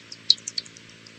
PixelPerfectionCE/assets/minecraft/sounds/mob/bat/idle1.ogg at ca8d4aeecf25d6a4cc299228cb4a1ef6ff41196e